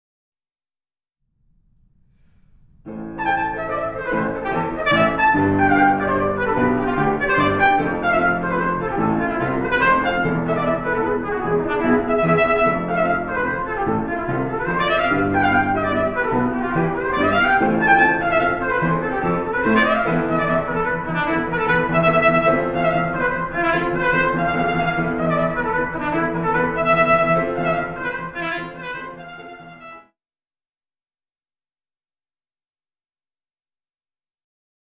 Excerpts are from Trumpet and Piano version